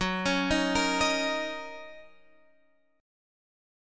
Gb6 Chord
Listen to Gb6 strummed